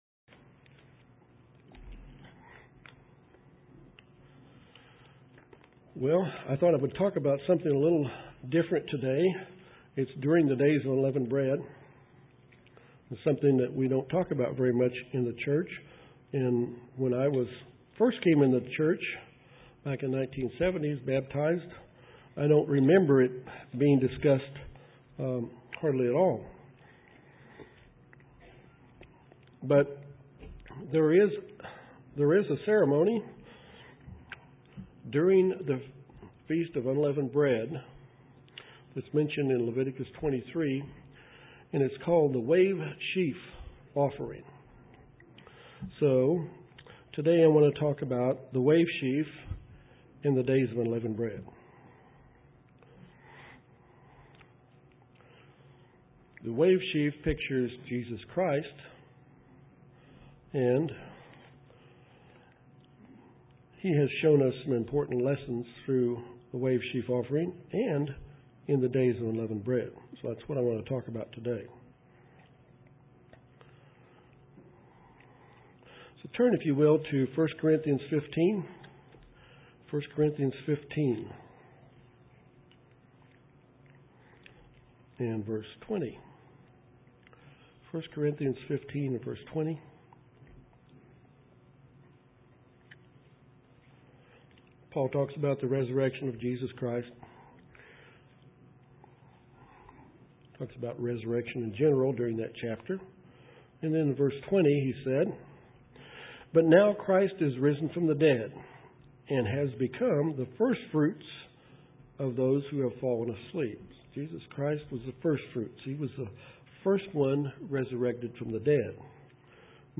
This sermon is about the meaning of the wavesheaf and the Days of Unleavened Bread.